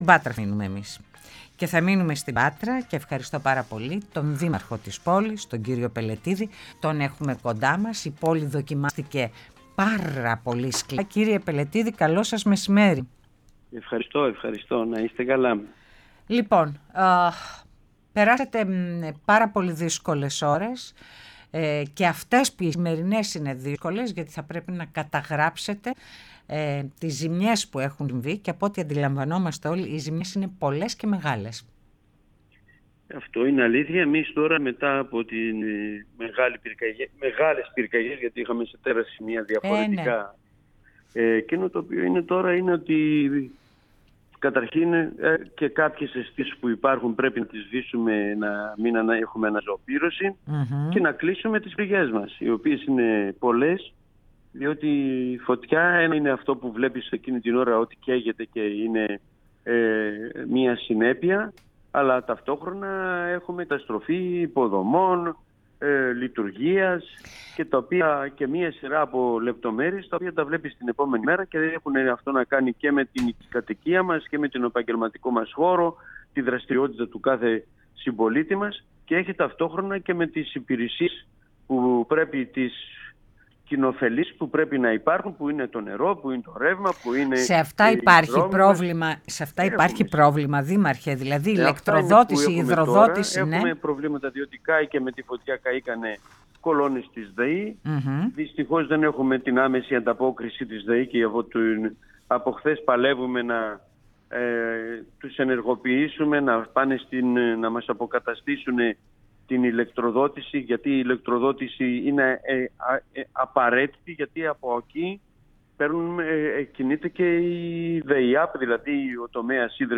Κ-Πελετιδης-δημαρχος-Πατρεων-στο-Πρωτο-Δεν-γινονται-οι-δαπανες-π.mp3